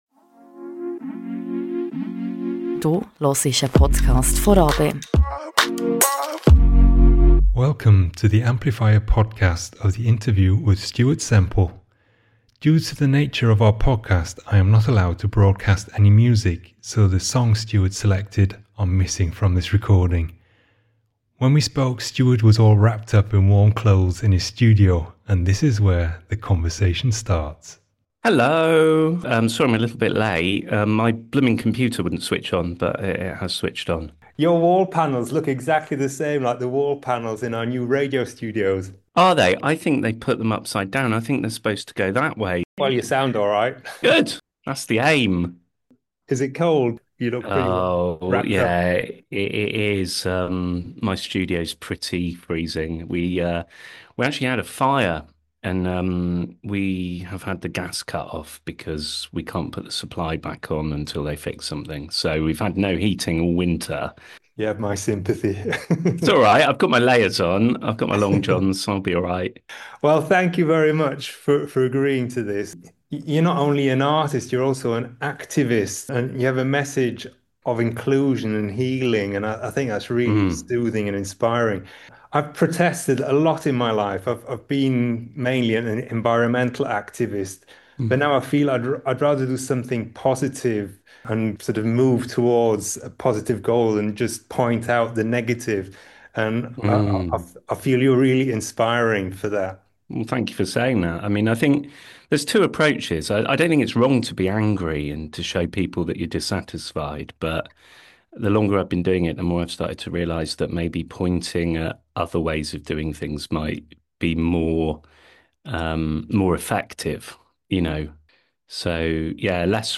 Amplifier Interview with Artist Stuart Semple ~ Radio RaBe Podcast